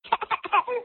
Cryinggg
cryinggg.mp3